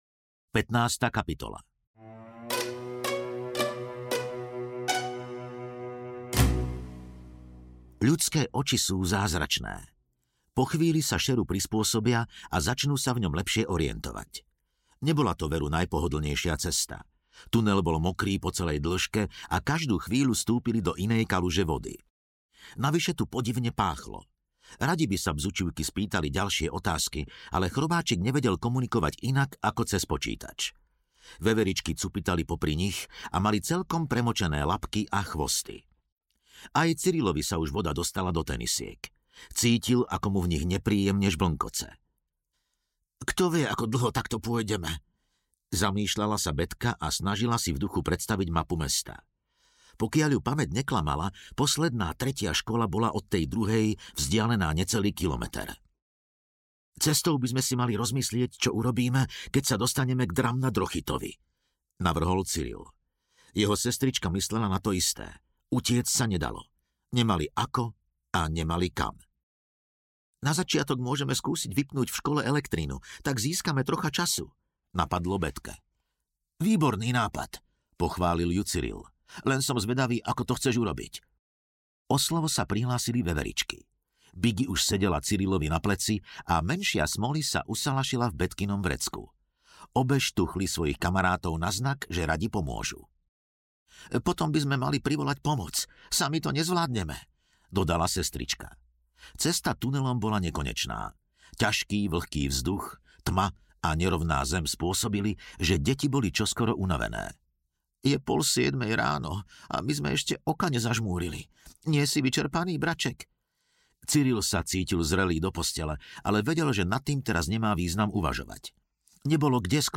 Kráľovskí agenti audiokniha
Ukázka z knihy